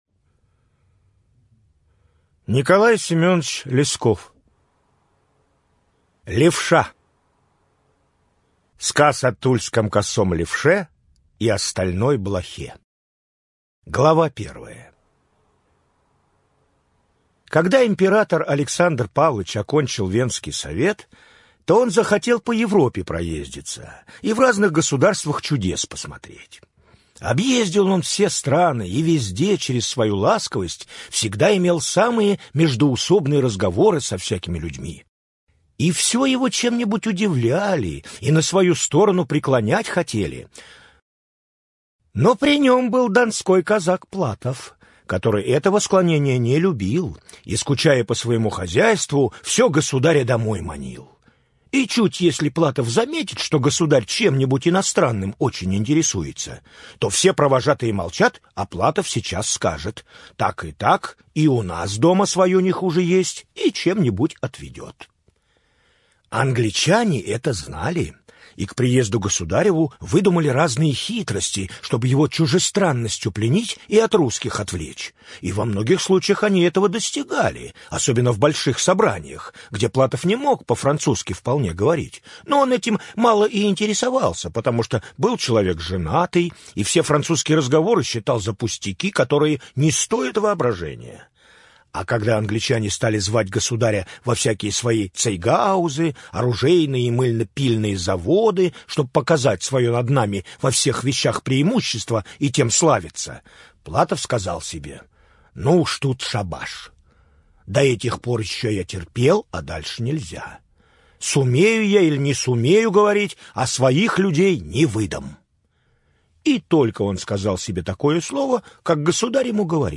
Левша - слушать аудиокнигу Лескова по главам